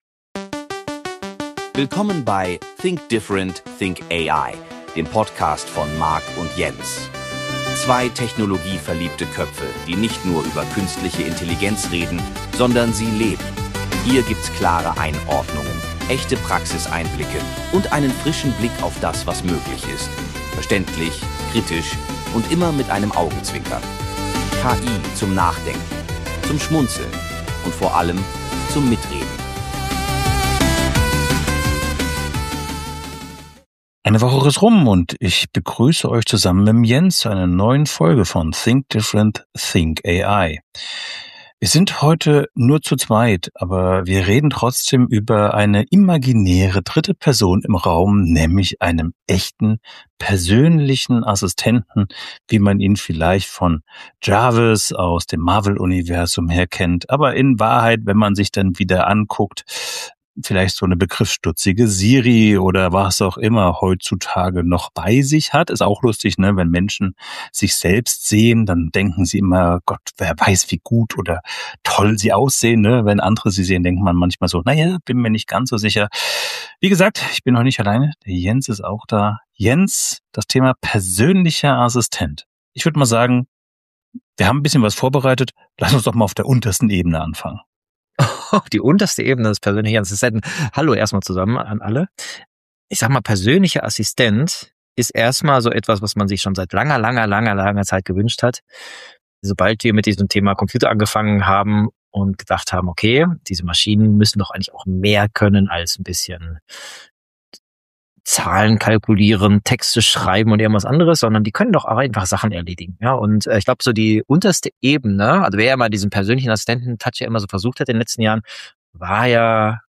In dieser Folge tauchen wir zu zweit tief in die Welt der persönlichen KI-Assistenten ein von den ersten sprachgesteuerten Helferlein wie Siri und Alexa bis hin zu den neuesten Entwicklungen wie Claude Code, Co-Work und dem heiß diskutierten Cloud-Bot.